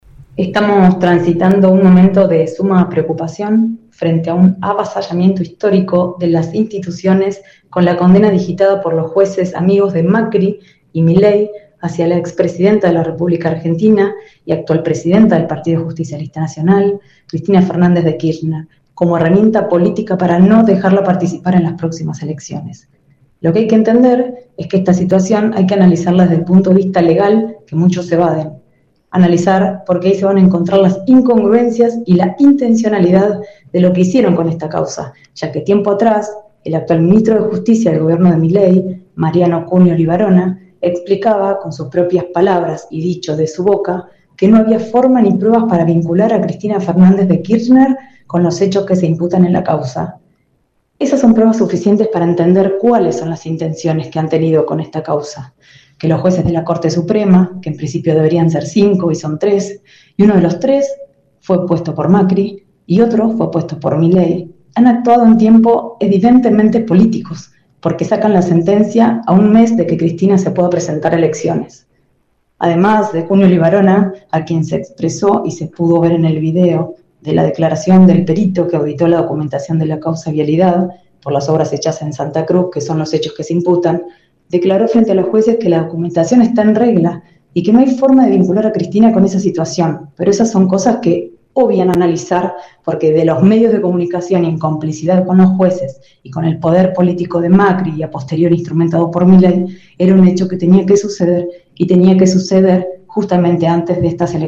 La concejal y referente kirchnerista local dejó su reflexión en la 91.5 sobre la condena a Cristina Kirchner por la causa Vialidad.